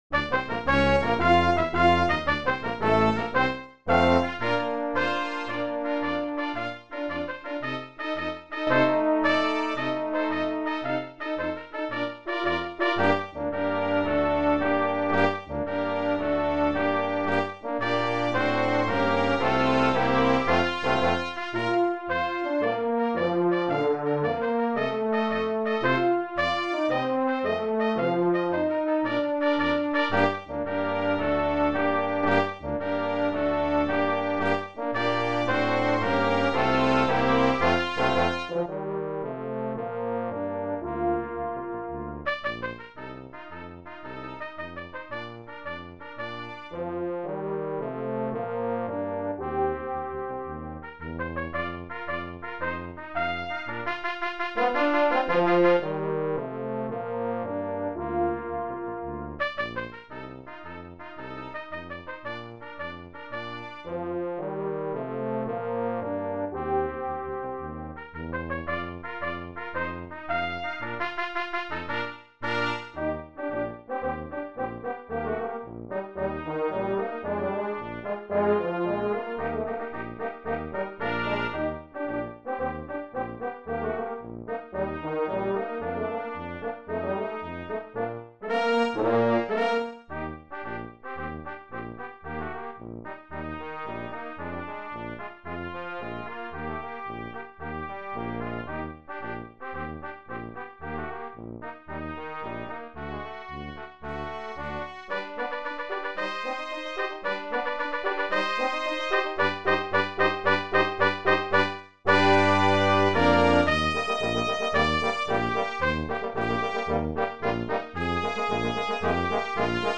Brass Quintet
Marches included in this collection: